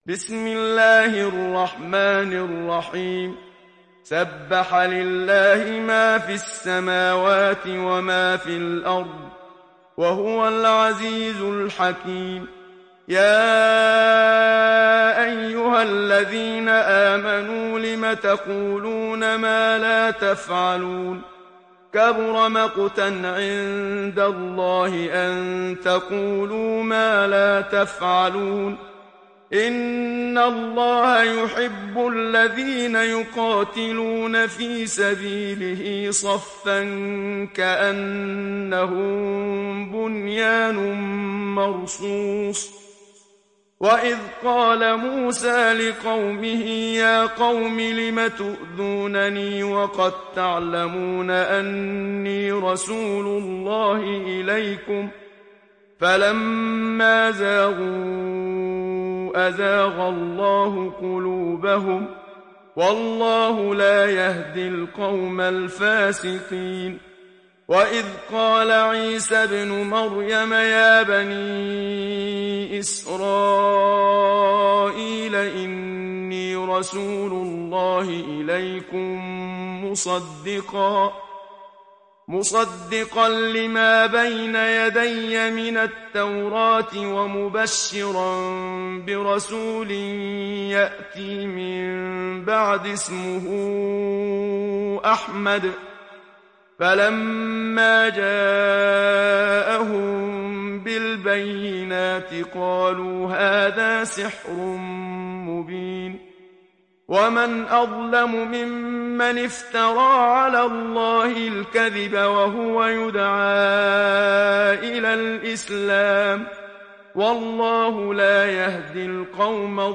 Saf Suresi İndir mp3 Muhammad Siddiq Minshawi Riwayat Hafs an Asim, Kurani indirin ve mp3 tam doğrudan bağlantılar dinle